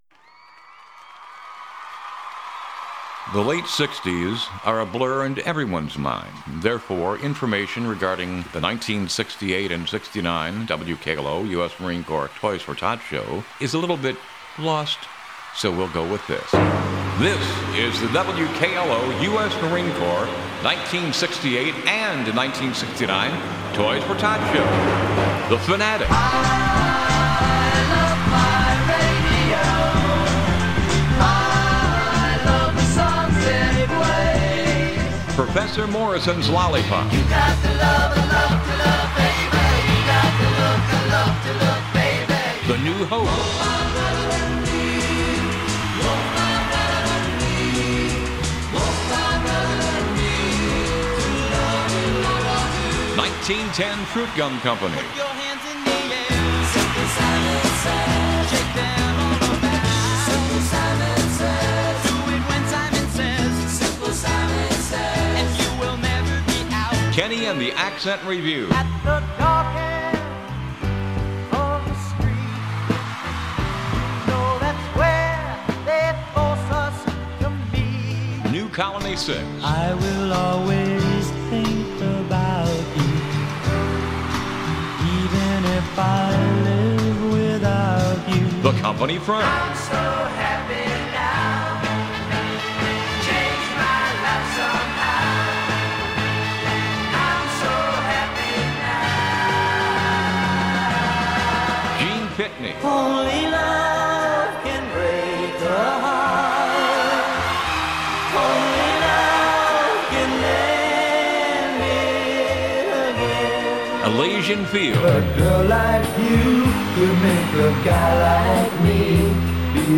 WKLO 1968 & 1969 Toys for Tots Audio Collage